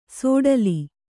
♪ sōḍali